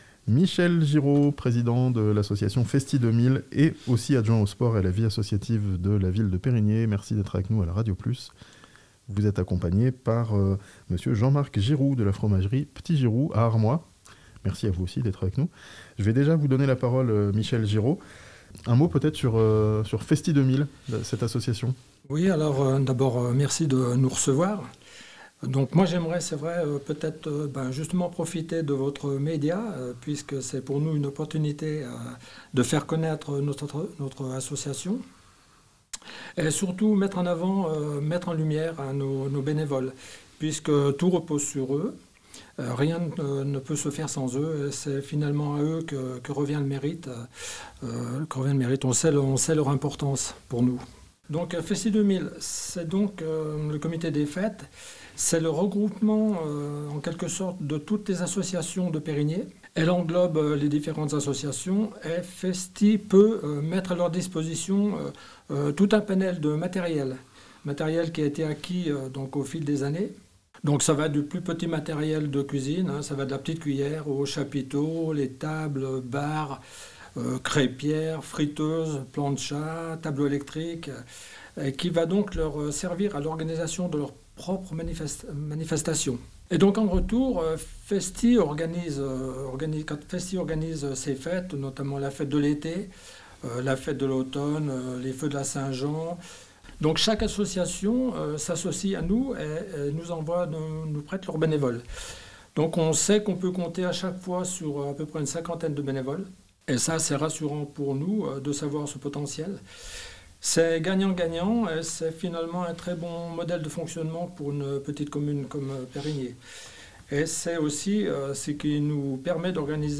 Le premier championnat de France de fondue savoyarde organisé dans le Chablais (interview)